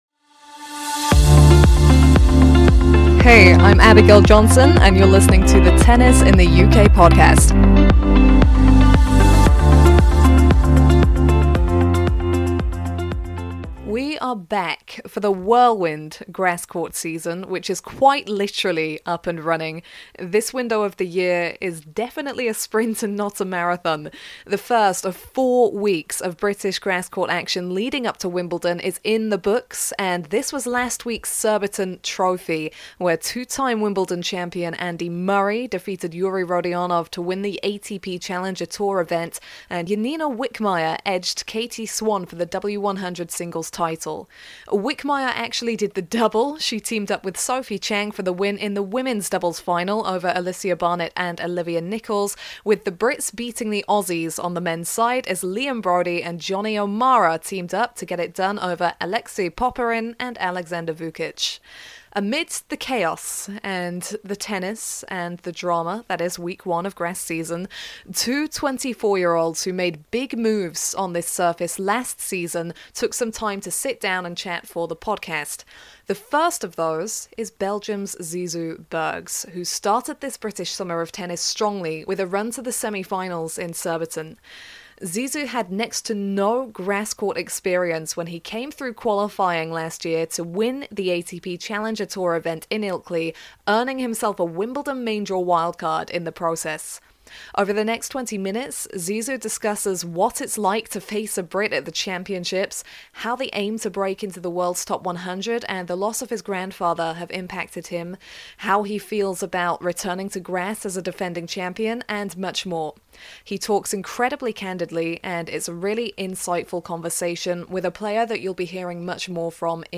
The British Summer of Tennis is underway! At the Surbiton Trophy